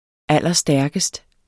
Udtale [ ˈalˀʌˈsdæɐ̯gəsd ]